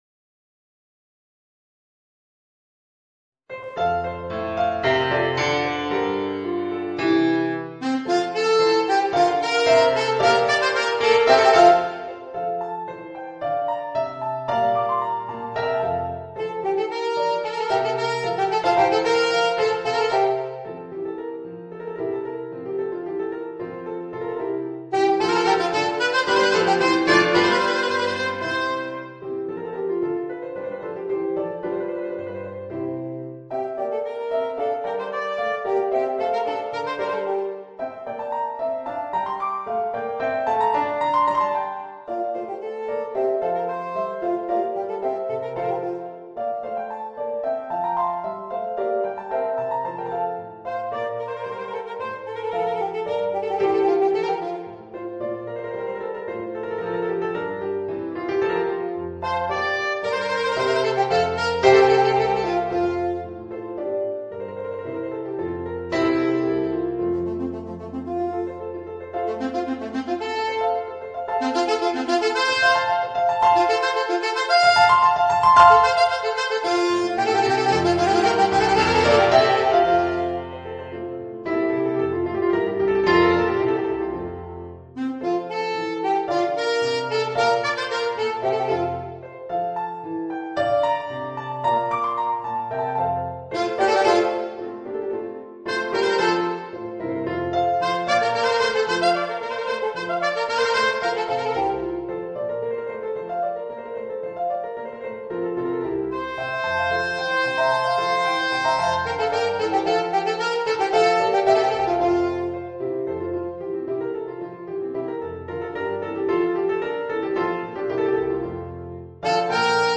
Voicing: Alto Saxophone and Organ